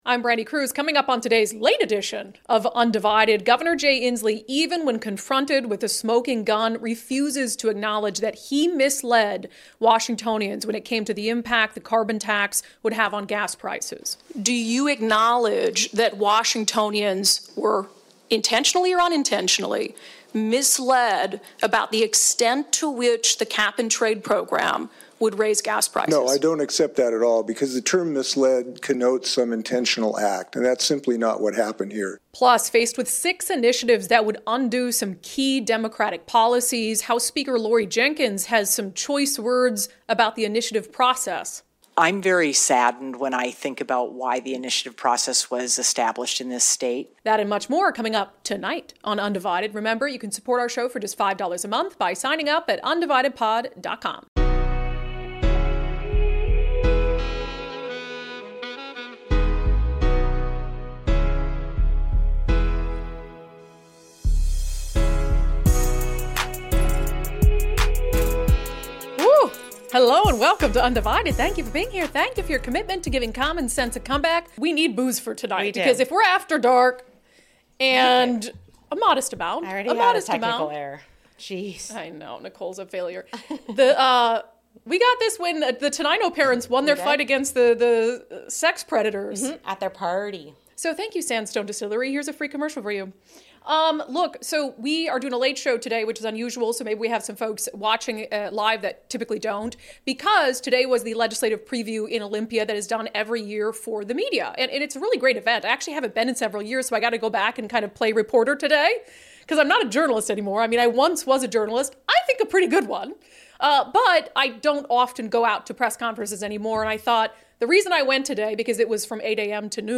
questions him at media availability. House Speaker Laurie Jinkins has some choice words about the initiative process.